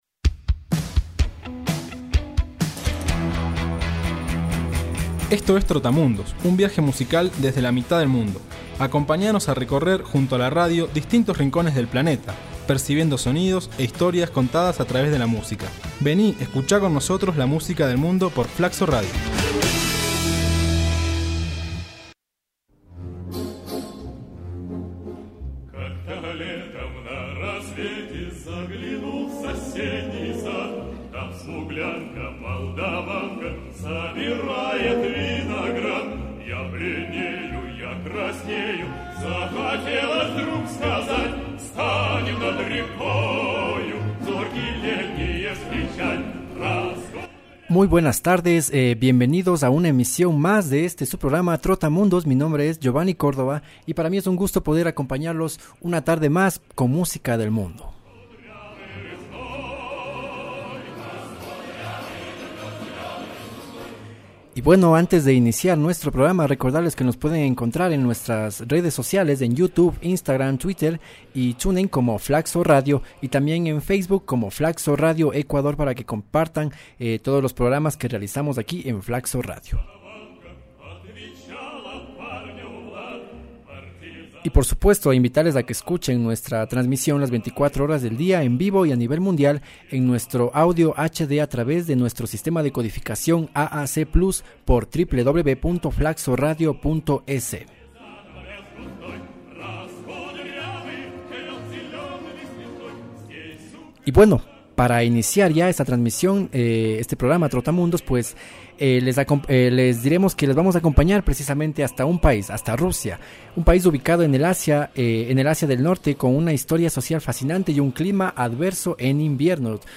coros